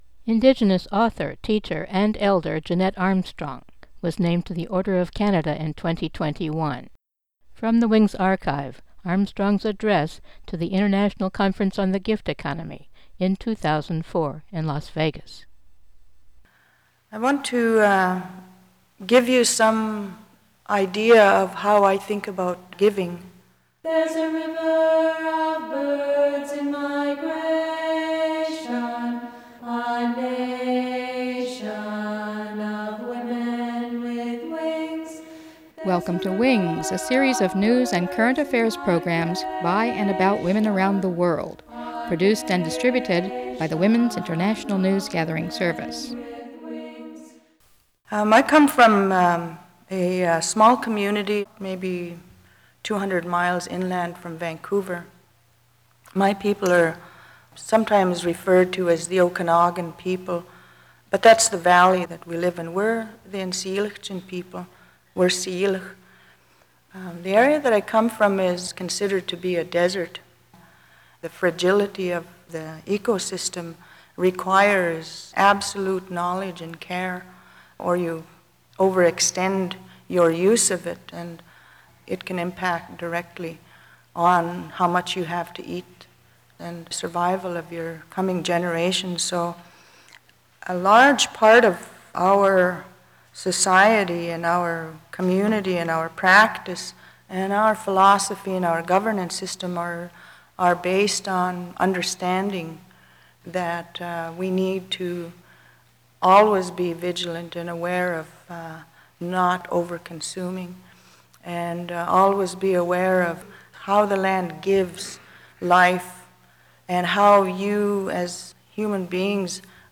File Information Listen (h:mm:ss) 0:28:45 WINGS22-21JeannetteArmstrong-28_46-320kbps.mp3 Download (20) WINGS22-21JeannetteArmstrong-28_46-320kbps.mp3 69,037k 320kbps Mono Comments: Talk to the International Conference on the Gift Economy, Las Vegas, 2004.